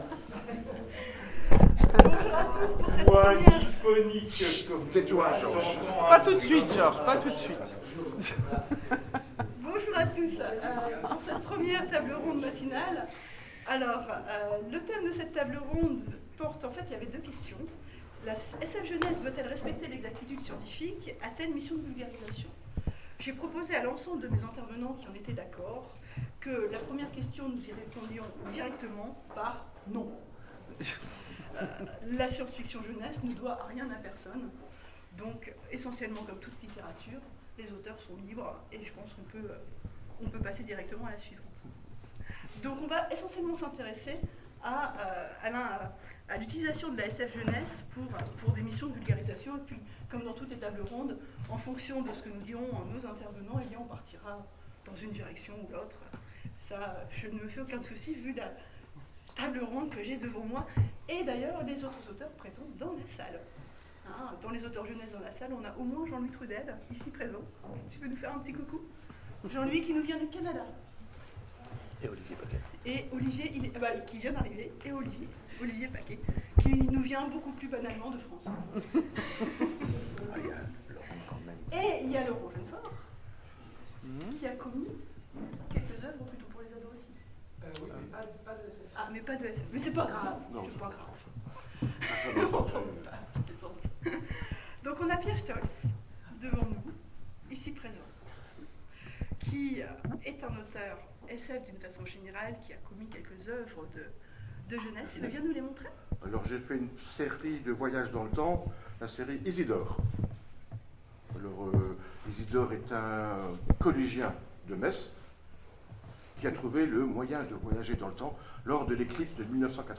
Convention SF 2016 : Conférence SF et Jeunesse